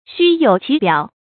注音：ㄒㄩ ㄧㄡˇ ㄑㄧˊ ㄅㄧㄠˇ
虛有其表的讀法